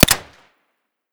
shoot_silenced.ogg